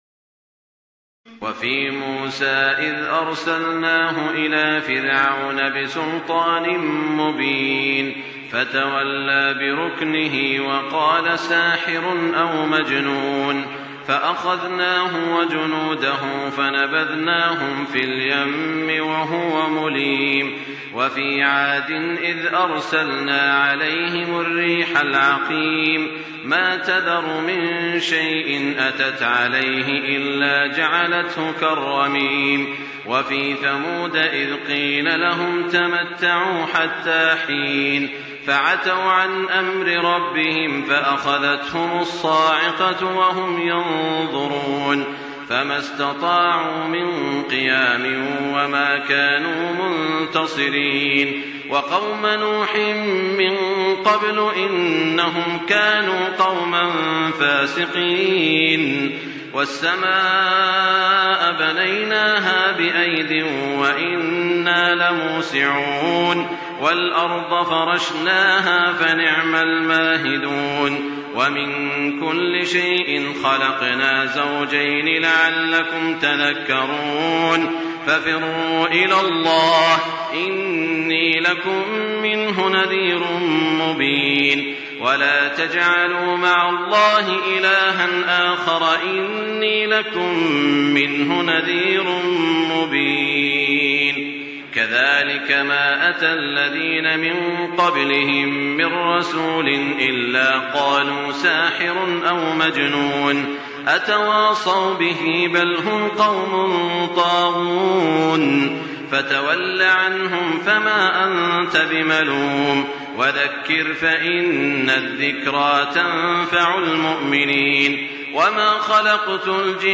تراويح ليلة 26 رمضان 1424هـ من سور الذاريات(38-60) و الطور و النجم و القمر Taraweeh 26 st night Ramadan 1424H from Surah Adh-Dhaariyat and At-Tur and An-Najm and Al-Qamar > تراويح الحرم المكي عام 1424 🕋 > التراويح - تلاوات الحرمين